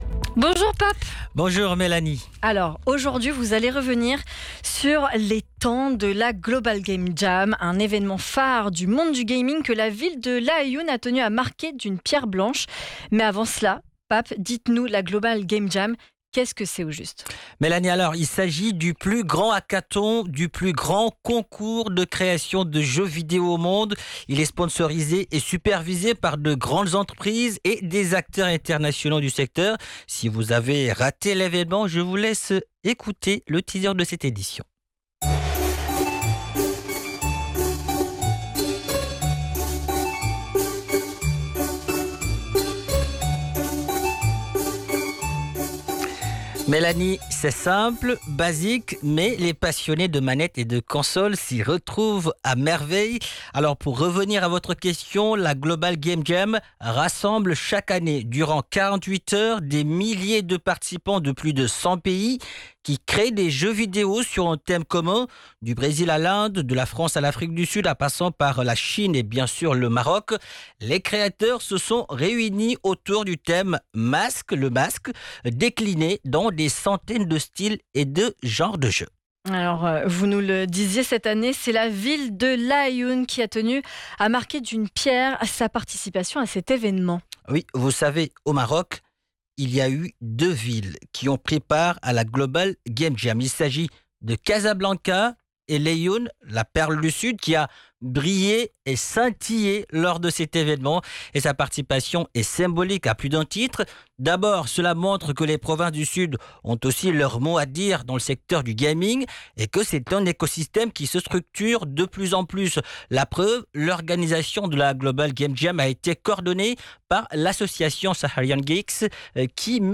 Featured on national radio, bringing visibility to Southern Morocco's emerging gaming scene and demonstrating that the industry can grow beyond major urban centers.